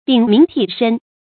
頂名替身 注音： ㄉㄧㄥˇ ㄇㄧㄥˊ ㄊㄧˋ ㄕㄣ 讀音讀法： 意思解釋： 猶言頂名冒姓。